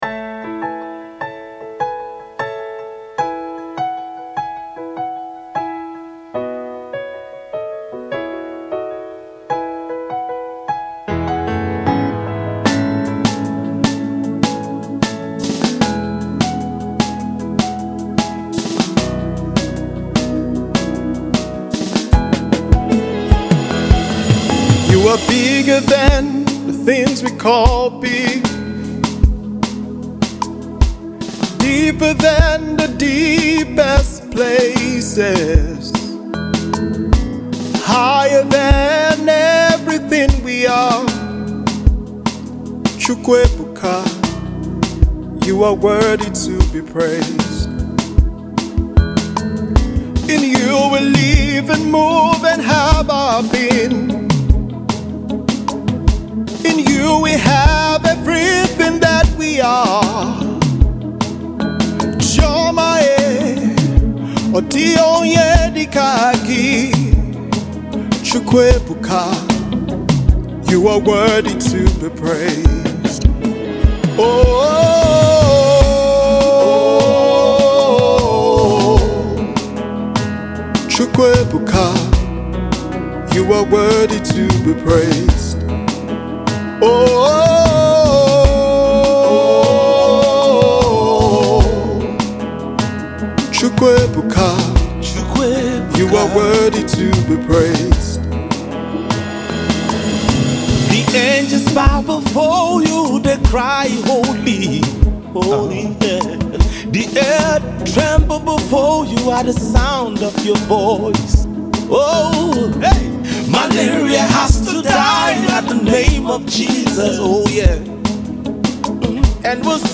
soul lifting worship tool